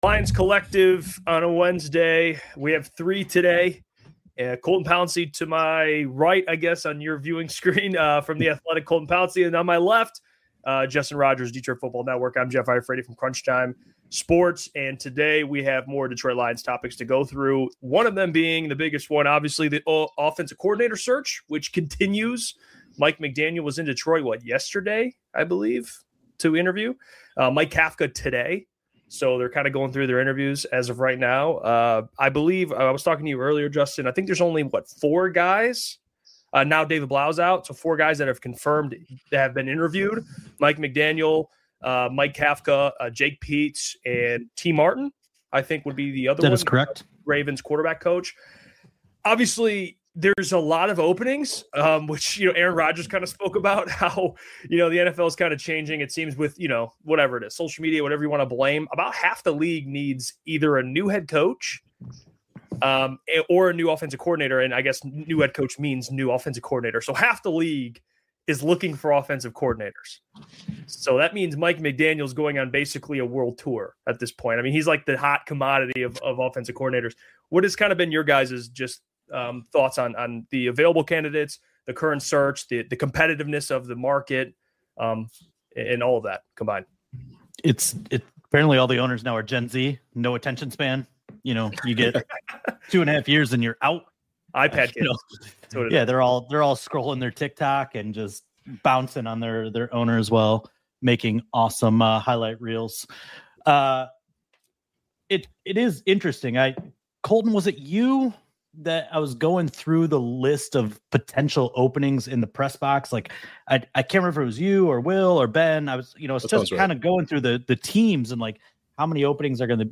Make sure to subscribe to Lions Collective for new roundtable episodes every week, live, on Wednesday at 6 pm ET You can also hear every episode of Lions Collective wherever you get your podcasts!